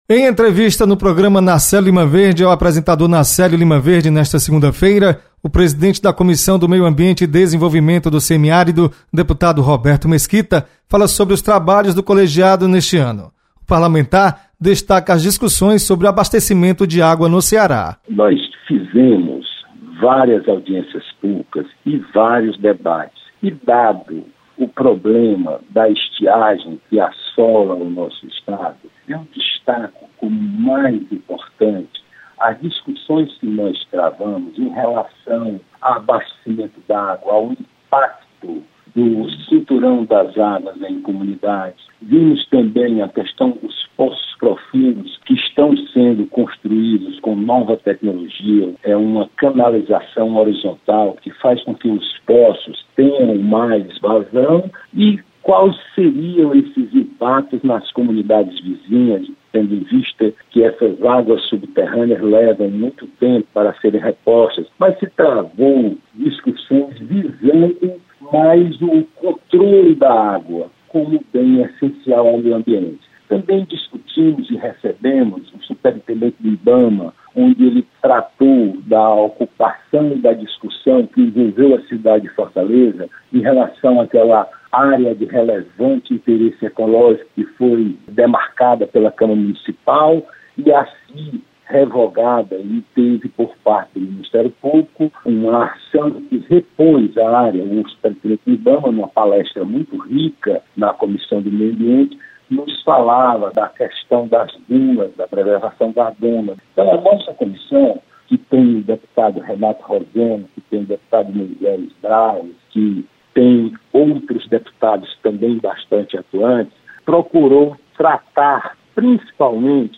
Deputado Roberto Mesquita faz balanço da Comissão do Meio ambiente e Desenvolvimento do Semiárido.